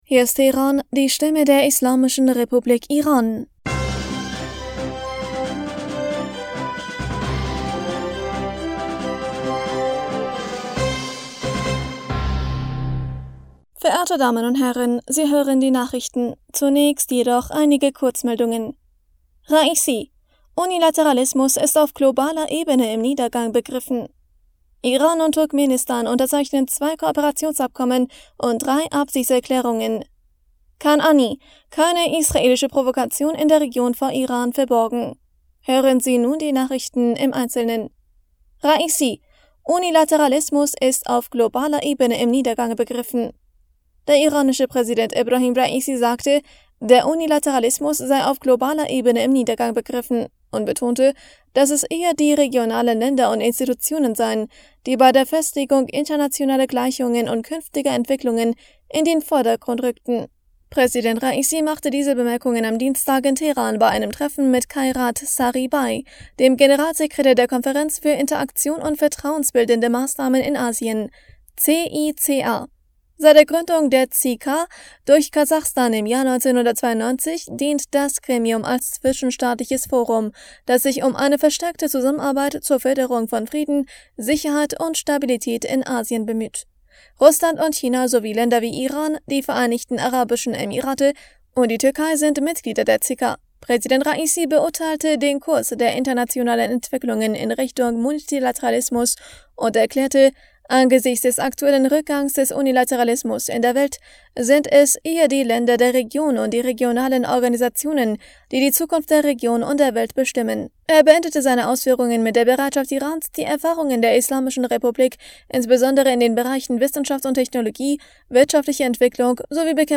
Die Nachrichten von Mittwoch, dem 31. Mai 2023